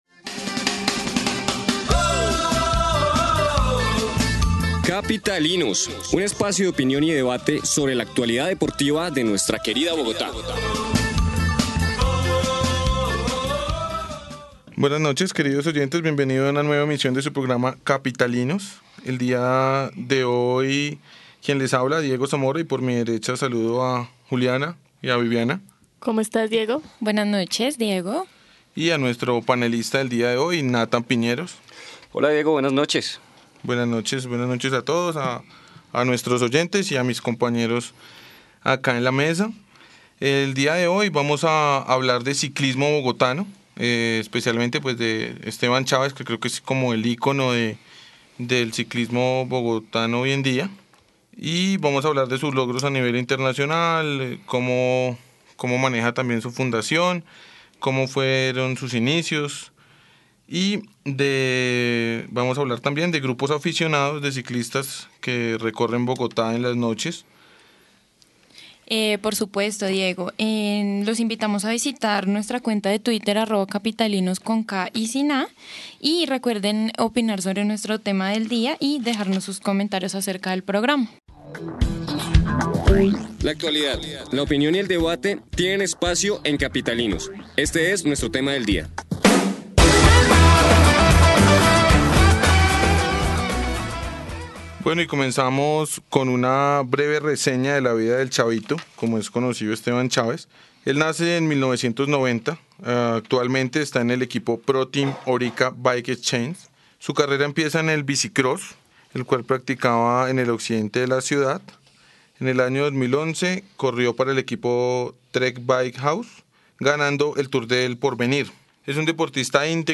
Presentaremos datos curiosos, voz Uniminuto con algunos de nuestros estudiantes de la universidad y la opinión actualidad Millonarios-Santa Fe, conducida por 2 de nuestros panelistas.